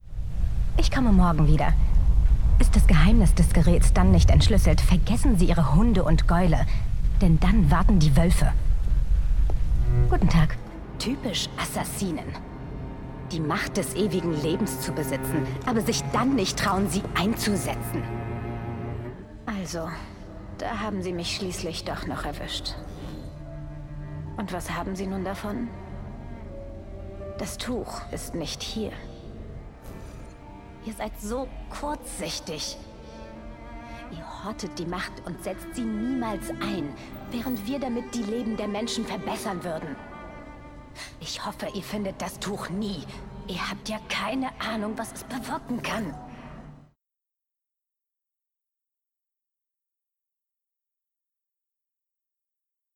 Videojuegos
Micrófono: Neumann TLM 103